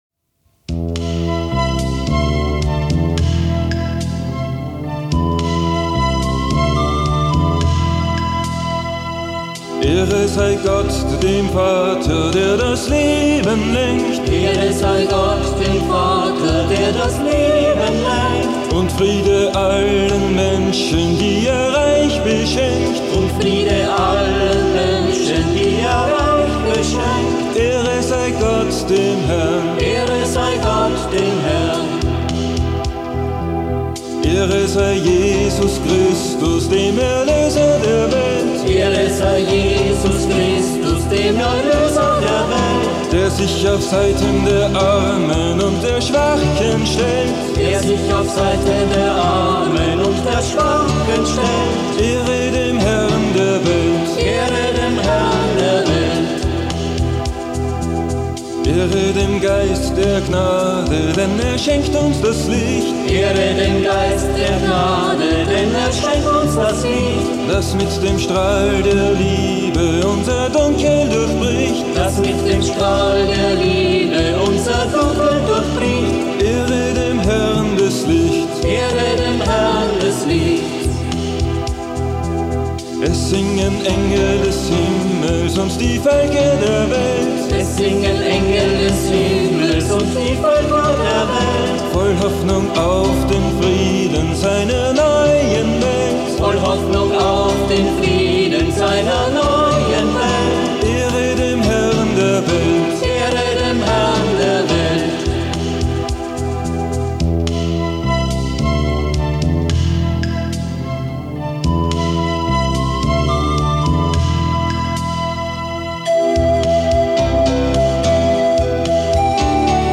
Brasilianische Messe